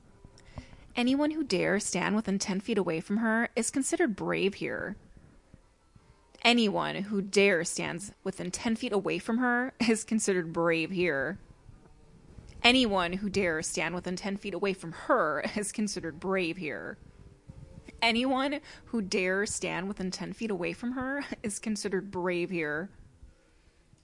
描述：这是我的声音，一个配音。女性是否与另一个角色说话。 它是用USB麦克风和大胆录制的。
Tag: 讲话 谈话 声音 女孩 美国 声乐 请求女人